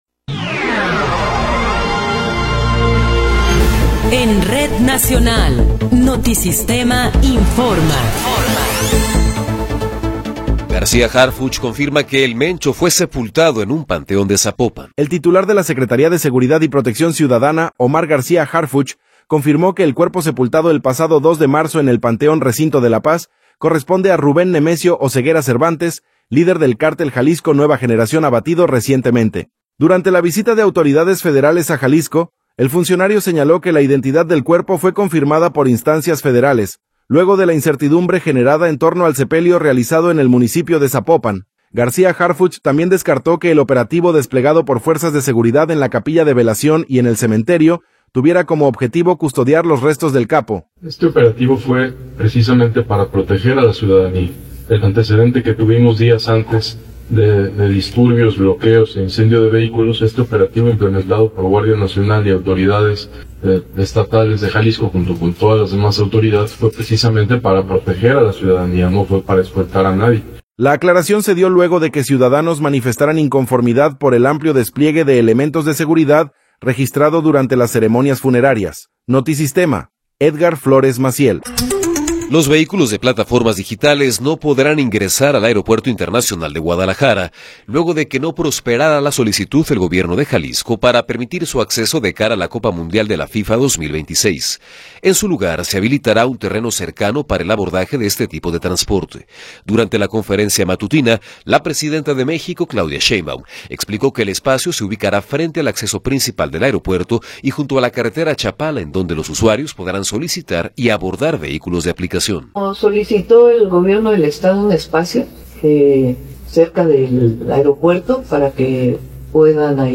Noticiero 12 hrs. – 6 de Marzo de 2026